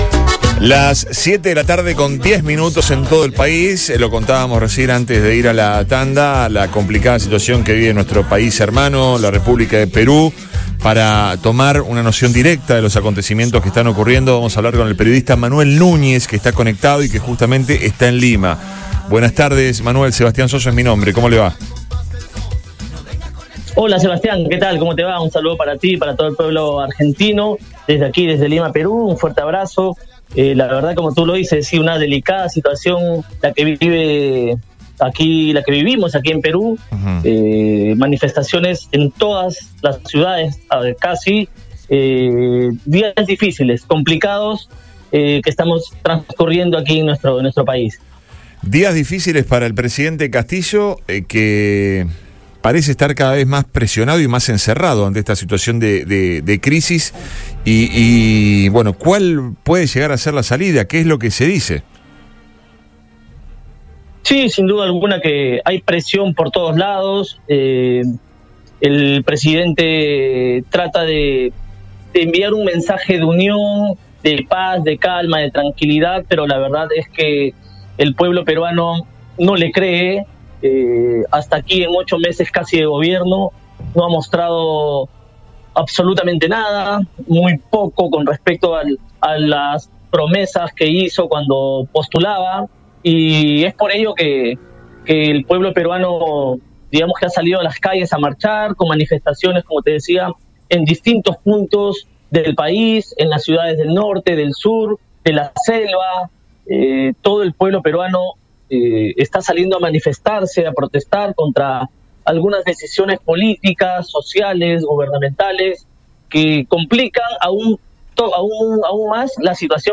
habló en Radio Boing desde Lima sobre la complicada realidad social que atraviesa el país vecino y que parece no tener fin todavía.